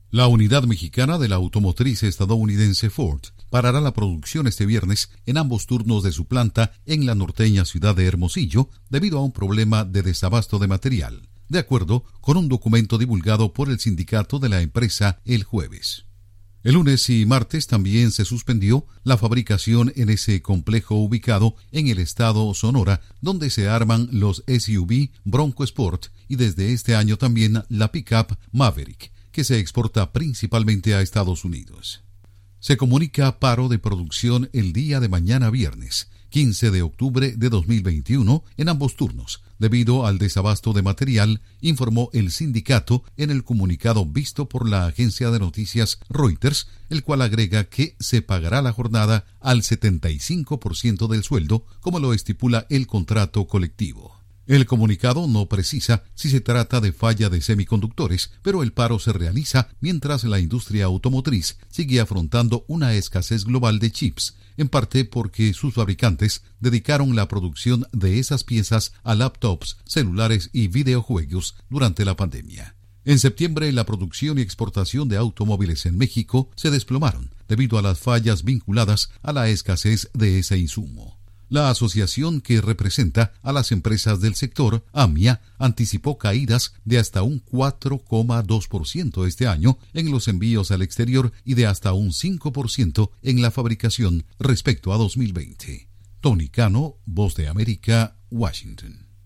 Ford suspenderá producción este viernes en planta mexicana Hermosillo por falta materiales. Informa desde la Voz de América en Washington